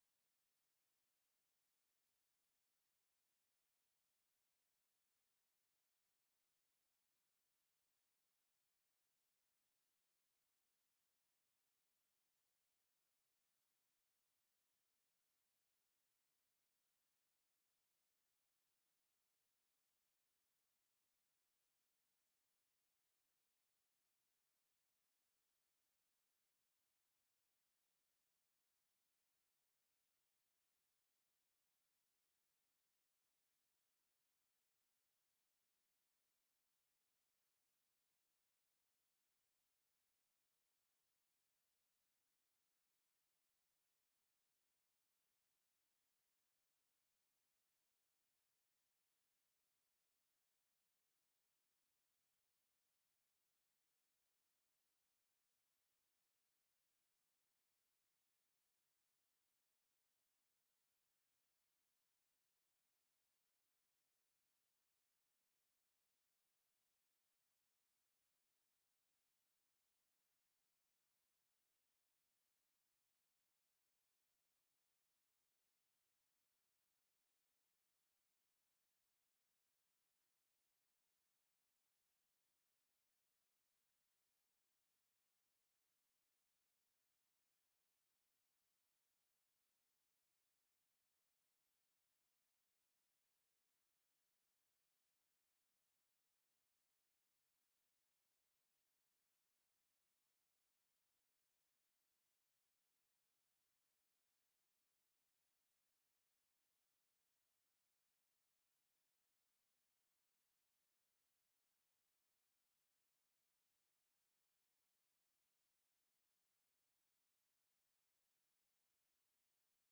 پایگاه اطلاع رسانی هیات رزمندگان اسلام صوت های سخنرانی سخنرانان مطرح کشوری را برای شما در سایت هیات جمع آوری نموده است.